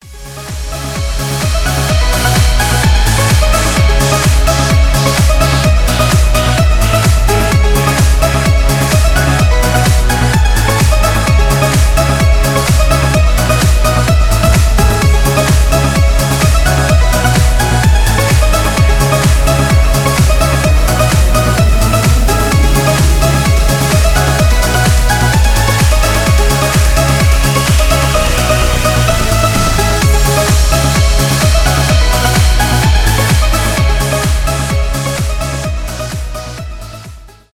транс , громкие
edm , клубные , мелодичные